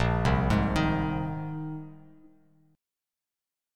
A#7sus2sus4 chord